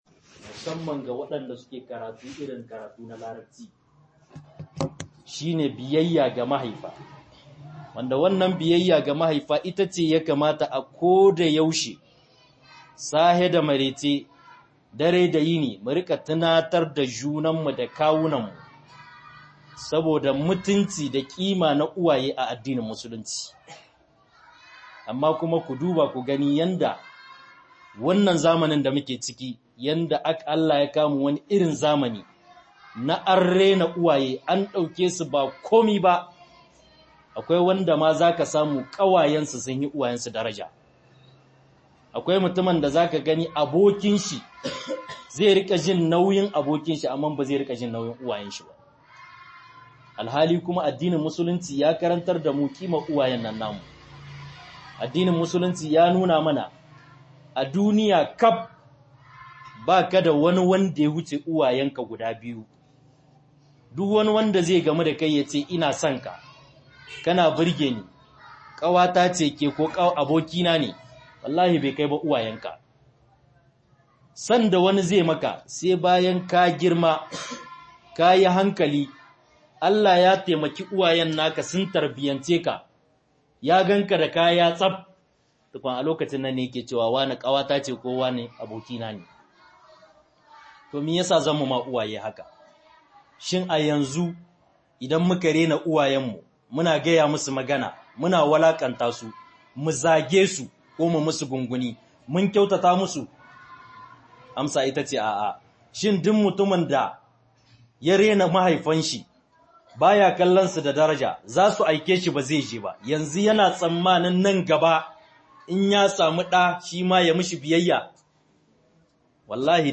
BIYAYA-GA-IYAYE - MUHADARA